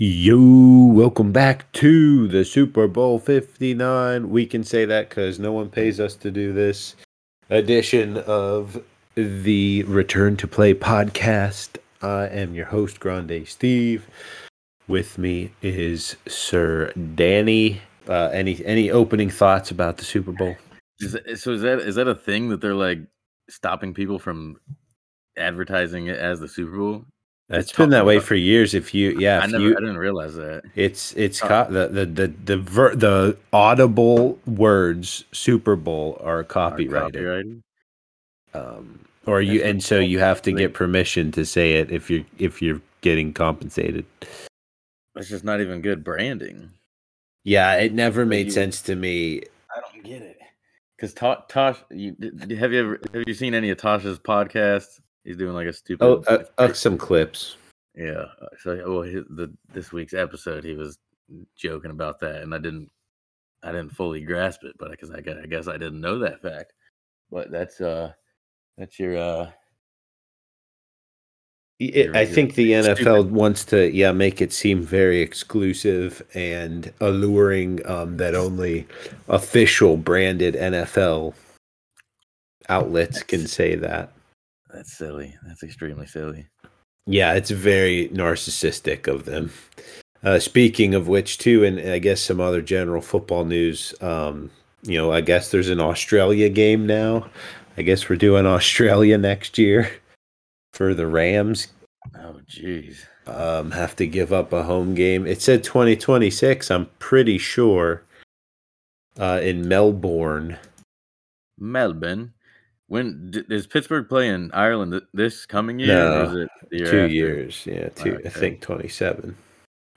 Three guys with a combined 21 years of school and zero sh*ts were given, provide weekly NFL injury news and discussions to help you make informed fantasy football and gambling decisions.